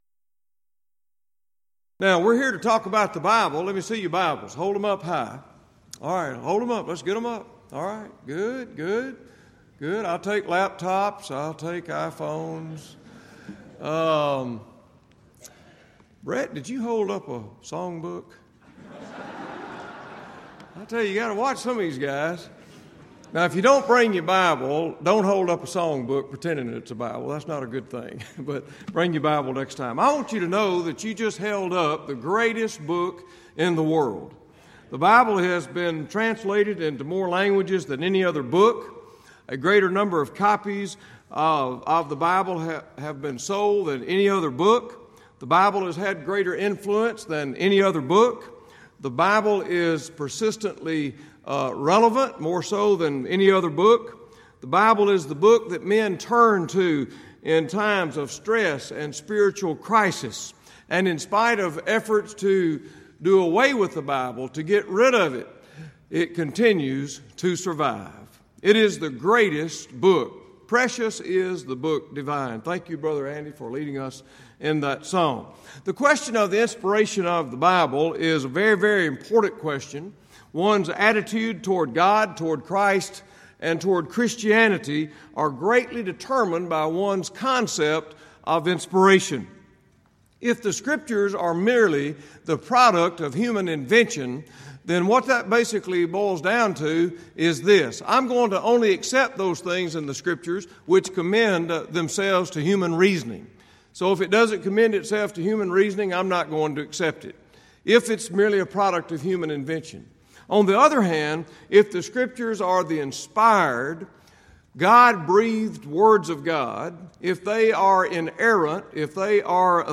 Alternate File Link File Details: Series: Southwest Lectures Event: 32nd Annual Southwest Lectures Theme/Title: Why Do We...
lecture